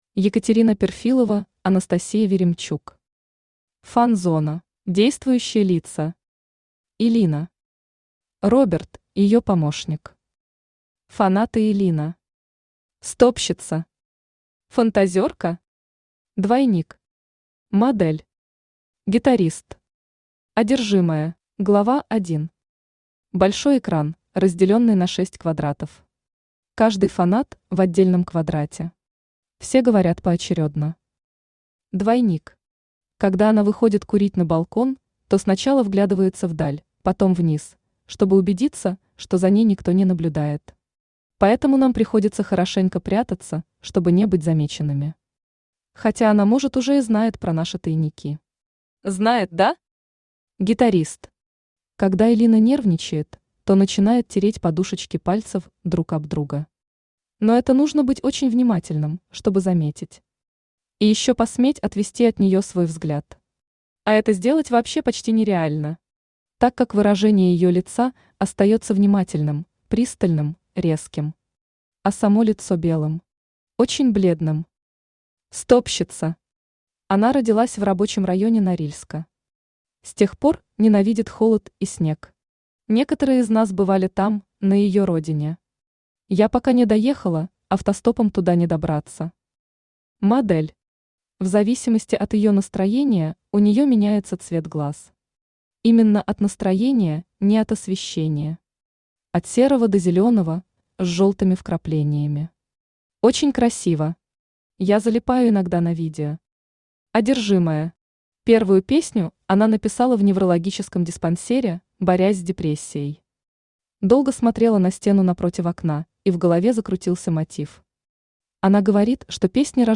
Aудиокнига Фан-зона Автор Екатерина Перфилова Читает аудиокнигу Авточтец ЛитРес.